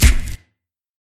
Snare (I Think).wav